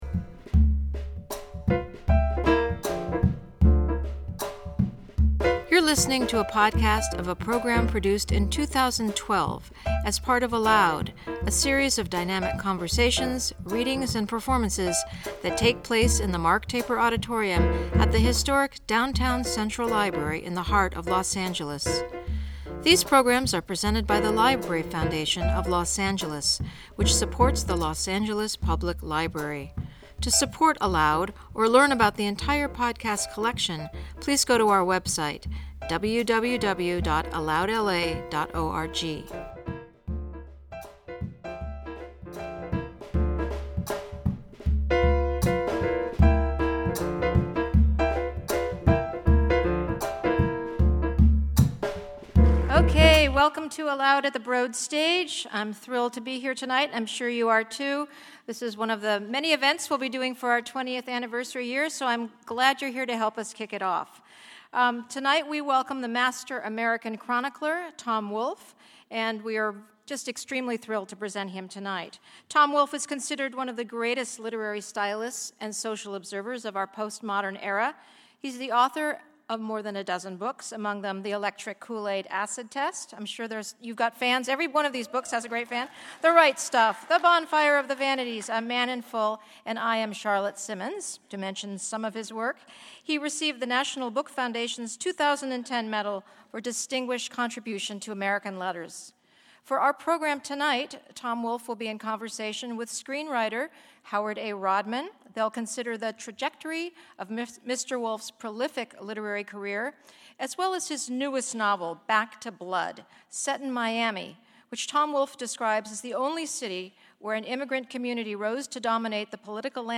In Conversation
With Actor René Auberjonois Performing a Dramatic Reading of Tom Wolfe’s Work